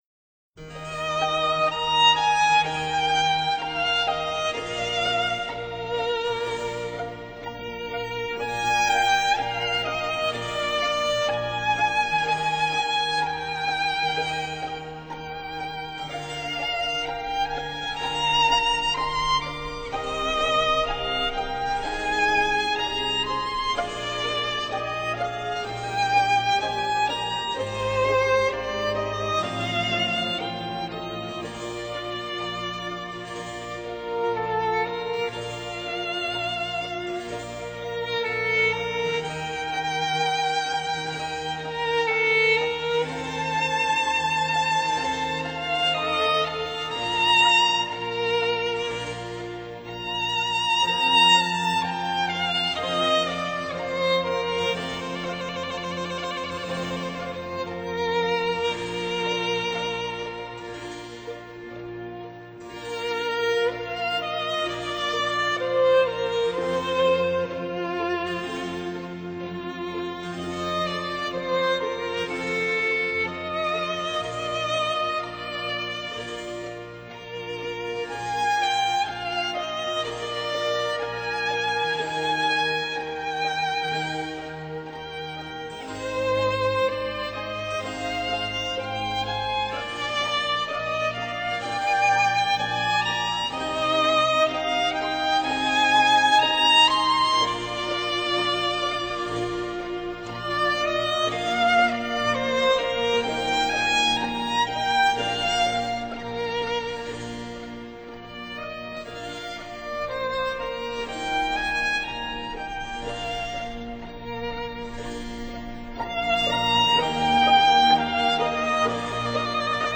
电影主题曲巴洛克版
以巴洛克管弦乐为 恋人们的相思苦而演奏、或者柔情绵绵的钢琴曲让您重温山盟海誓的时光！